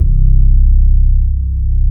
FRETLESSC2-R.wav